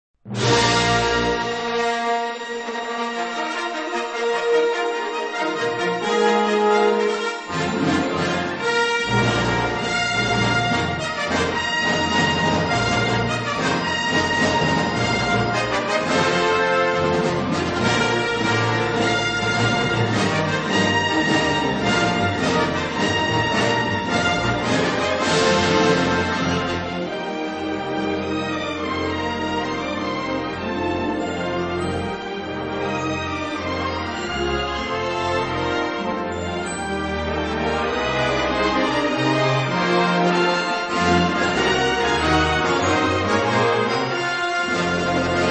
• Classical Ringtones